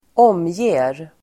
Uttal: [²'åm:je:r]